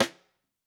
Index of /musicradar/Snares/Piccolo Sidestick
PearlPiccolo Side SnrOn-05.wav